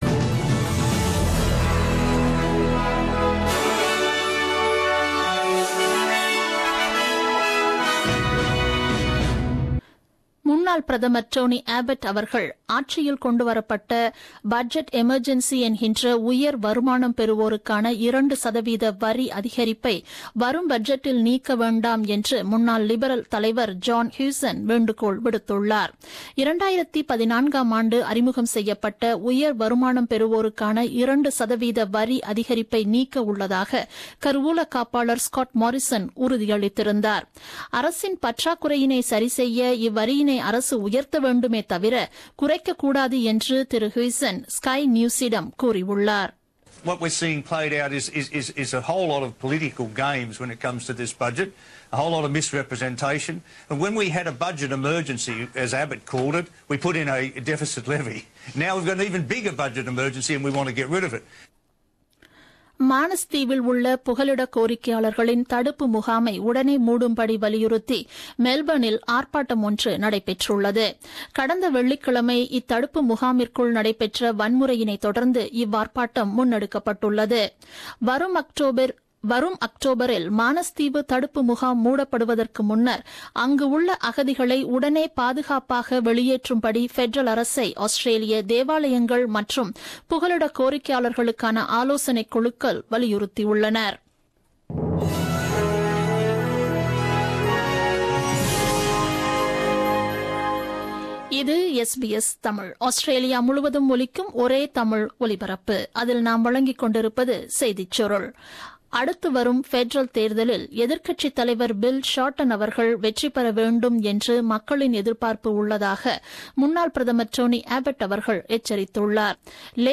The news bulletin broadcasted on 17th April 2017 at 8pm.